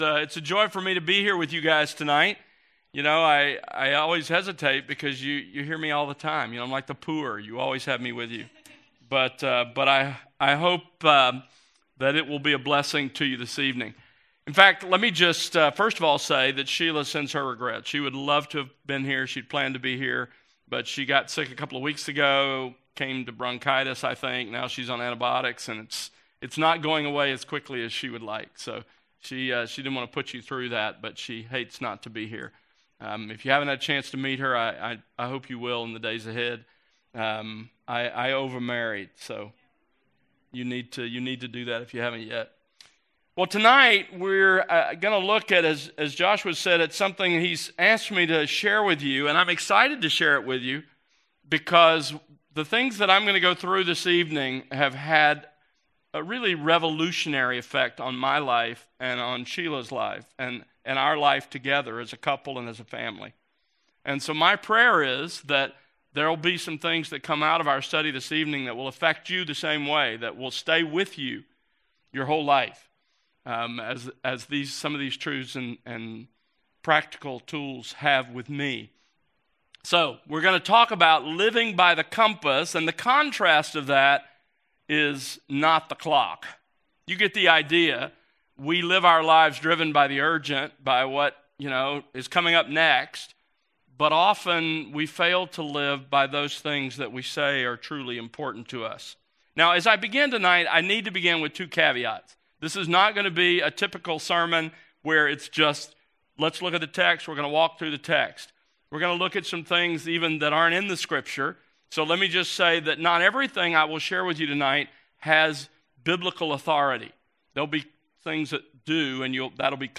Beginnings Beginnings - Fall Retreat 2024 - A Biblical Everyday Life Audio Slides Series List Next ▶